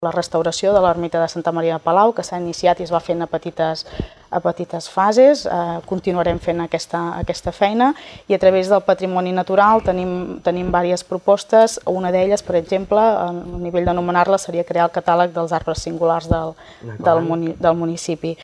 Debat Electoral Torroella de Montgrí - l'Estartit 2019
Un dia en què l’agenda vindrà marcada per les propostes que es van llançar ahir des de l’estudi a l’hotel Mas de Torrent des d’on cada vespre emetem un dels nostres debats.